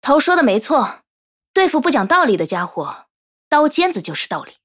【模型】GPT-SoVITS模型编号071_女-secs